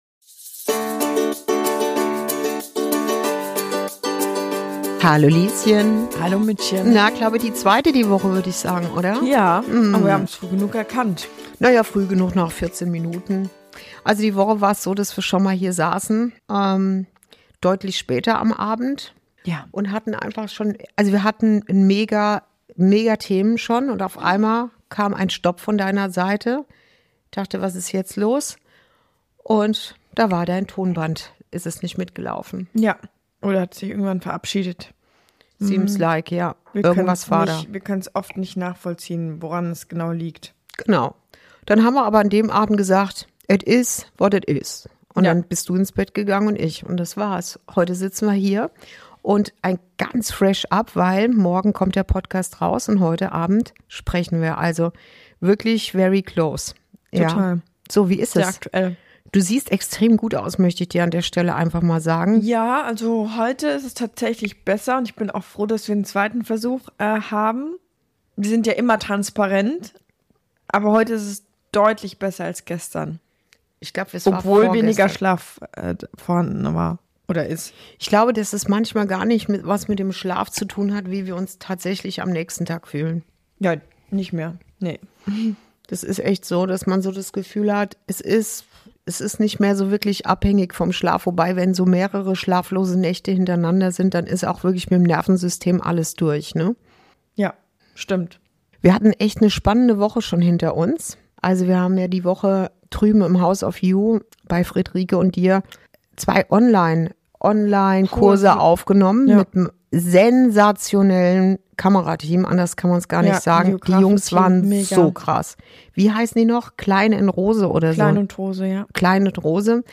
Es geht um das, was uns wirklich trägt, wenn es wackelt – und um den Mut, sich der eigenen Wahrheit zu stellen. Ein Gespräch zwischen Mutter und Tochter, voller Wärme, Witz und Weite.